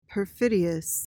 (puhr-FID-ee-uhs)